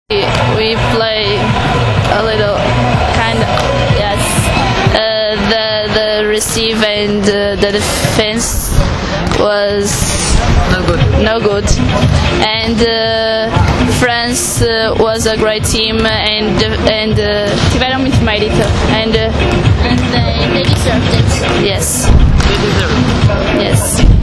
IZJAVA